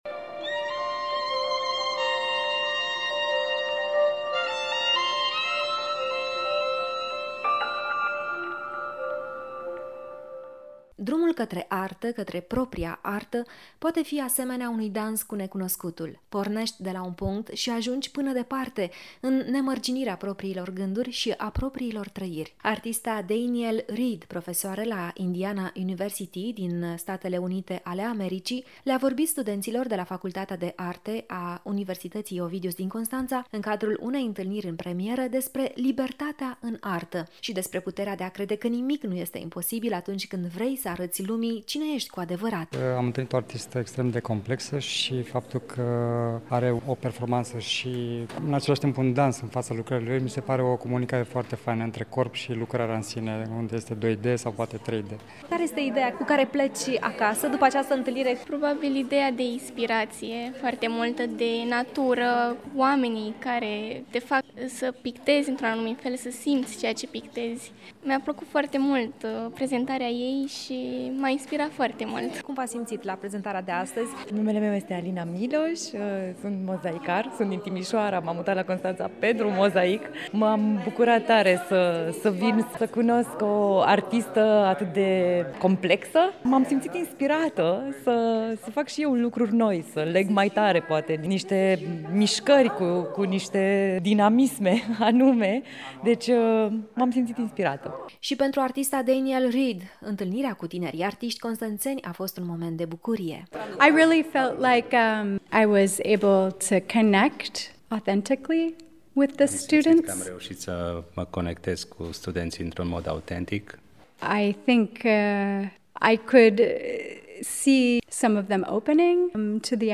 Atelierul cultural găzduit de Muzeul de Arta a fost organizat de Radio Constanța în parteneriat cu Facultatea de Arte a Universității Ovidius și Muzeul de Artă Constanța.
Reportajul integral